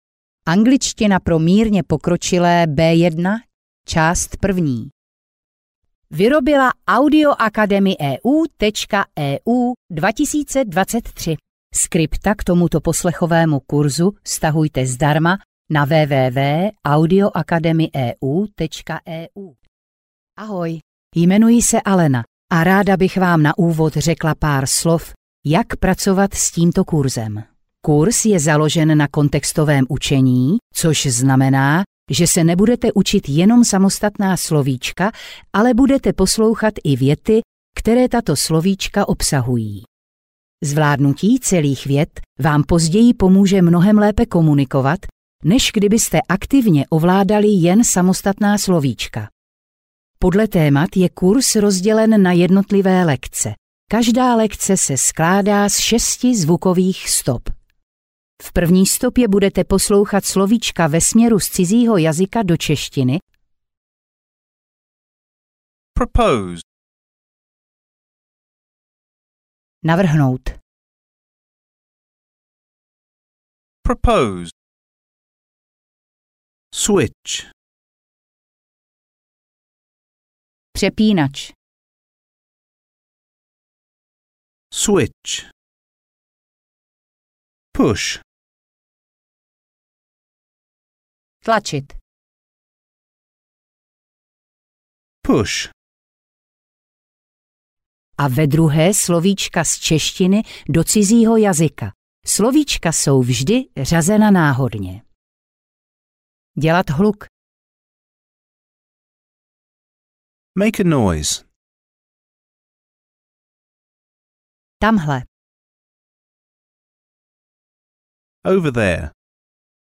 Audiokniha Angličtina pro mírně pokročilé B1 – část 01, kterou napsal Tomáš Dvořáček.
Ukázka z knihy